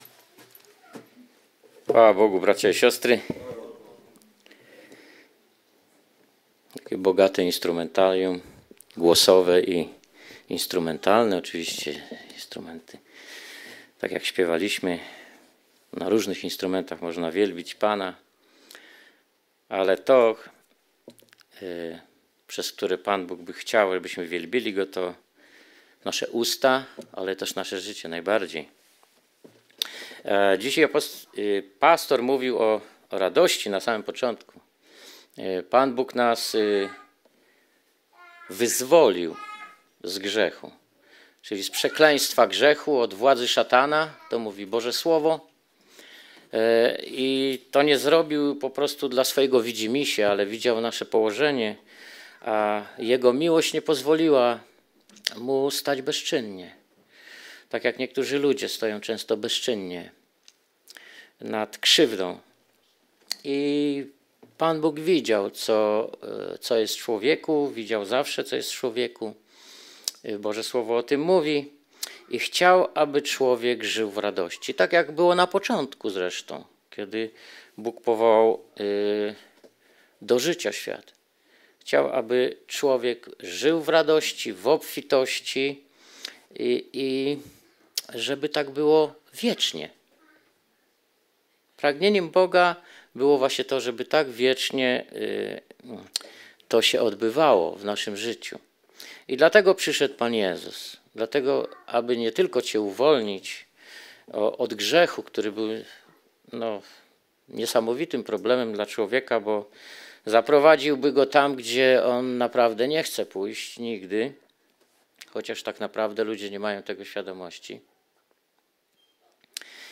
Skorzystaj z przycisku poniżej, aby pobrać kazanie na swoje urządzenie i móc słuchać Słowa Bożego bez połączenia z internetem.